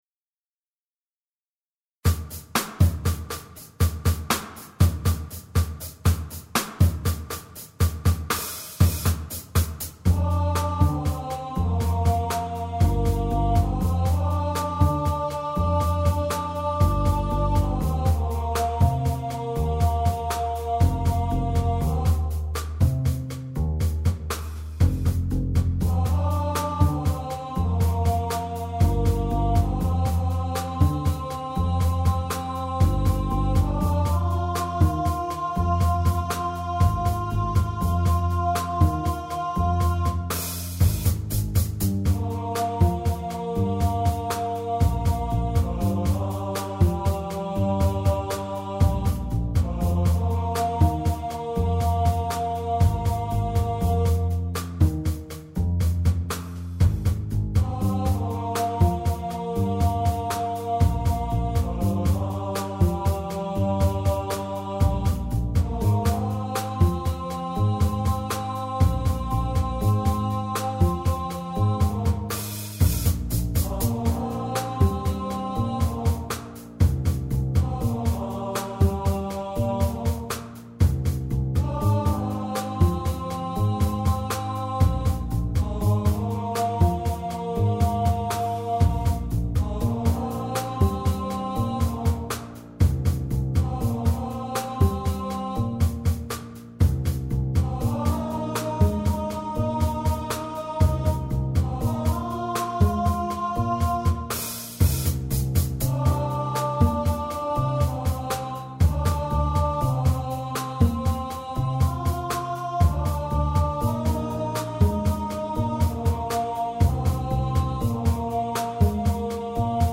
for Voices and Latin Jazz Ensemble Arrangement